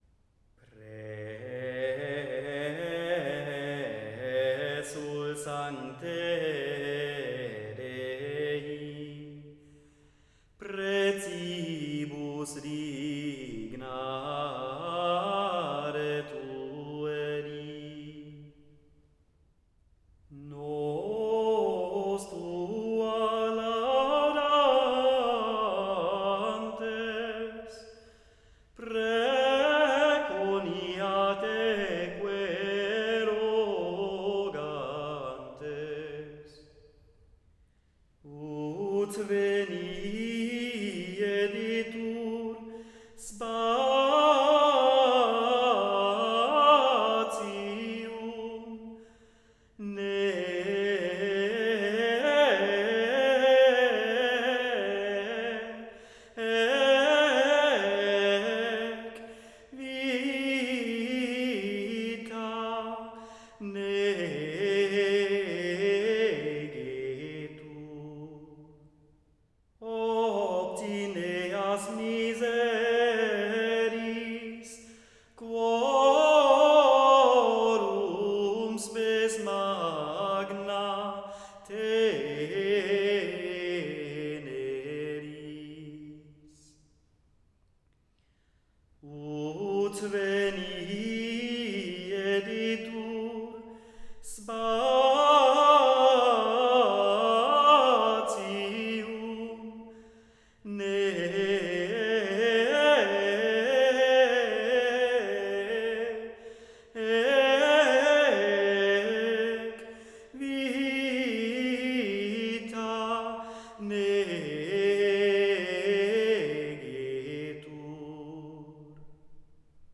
Responsorium_Presul_sancte.wav